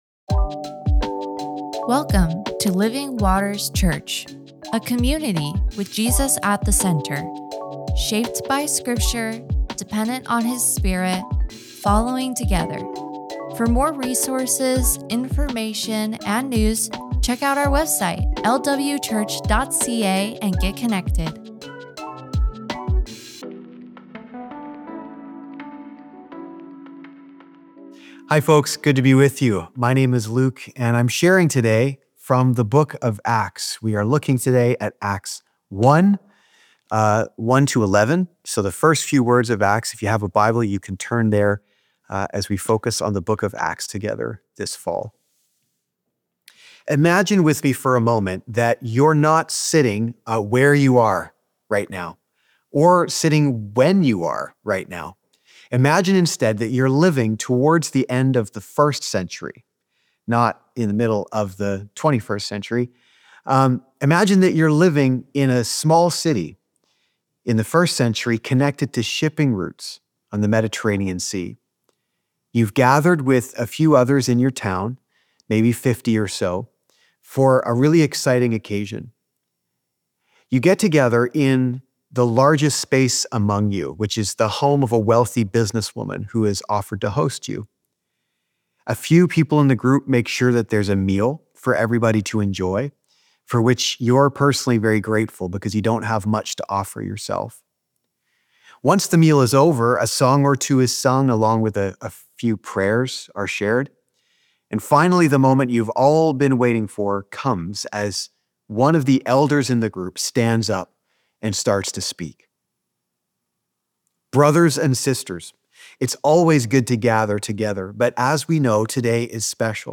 Sermons | Living Waters Church